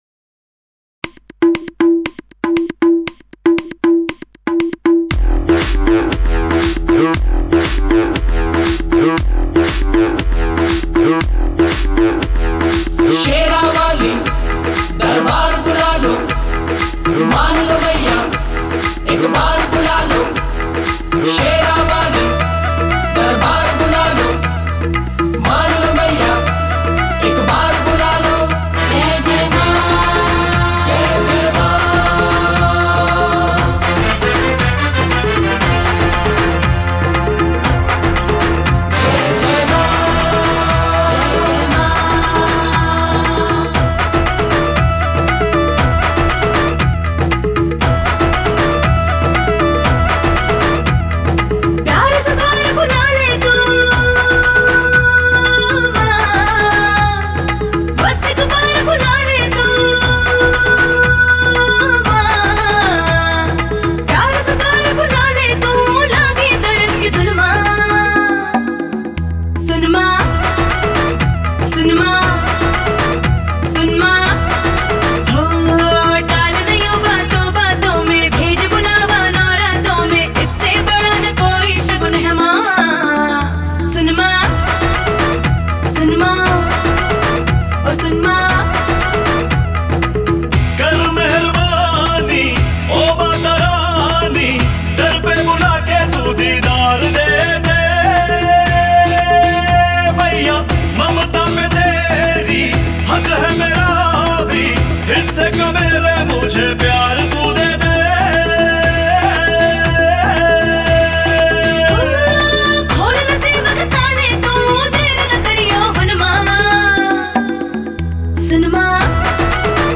bhajan album